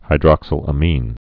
(hī-drŏksə-lə-mēn, hīdrŏk-sĭlə-mēn, -sə-lămĭn)